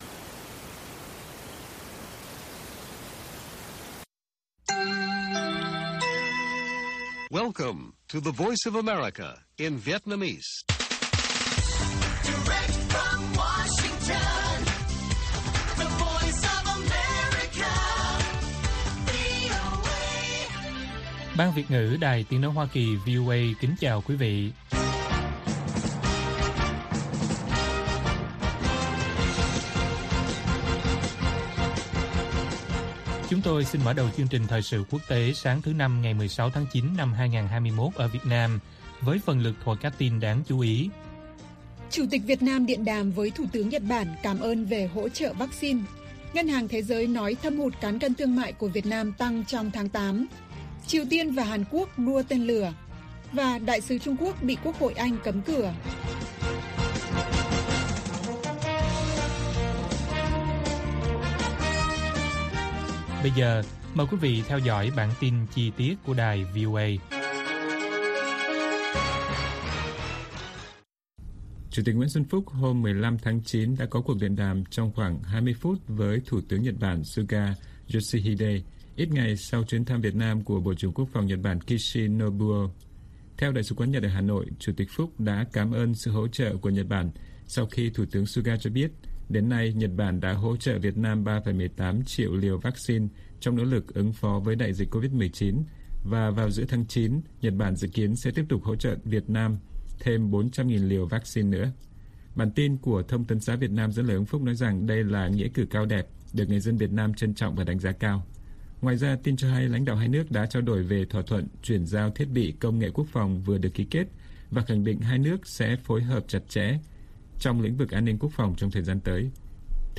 Bản tin VOA ngày 16/9/2021